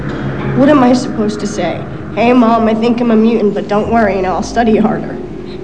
Sounds From the Generation X telefilm